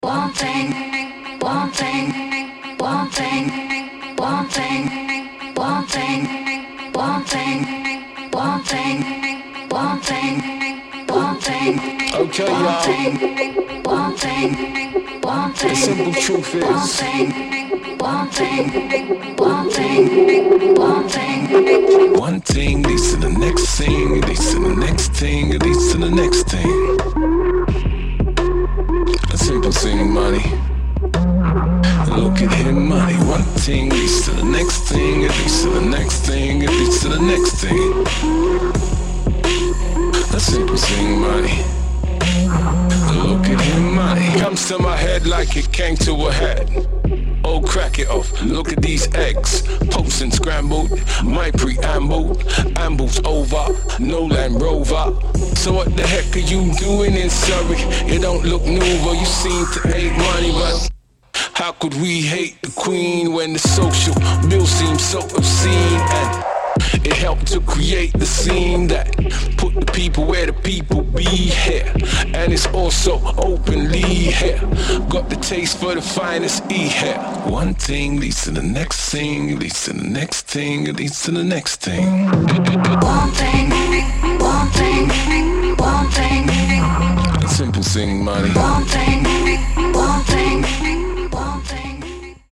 Hip Hop. Beats